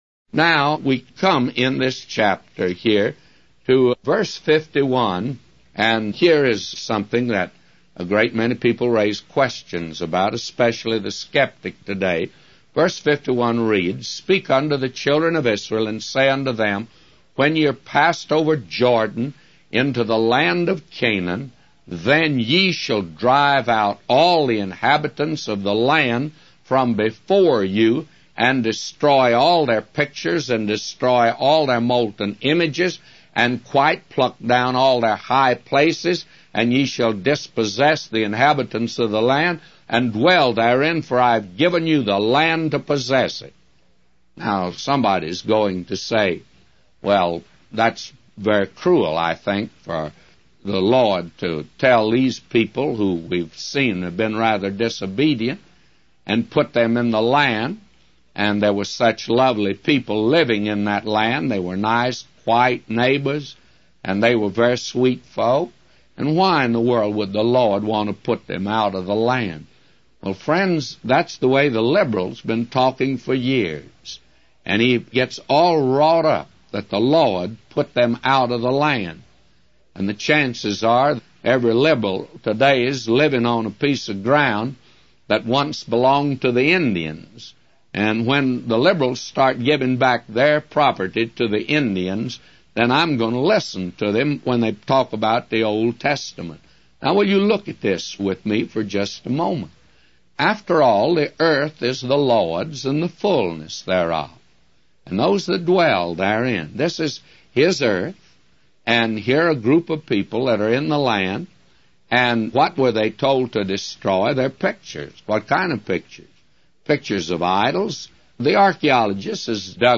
A Commentary By J Vernon MCgee For Numbers 33:51-999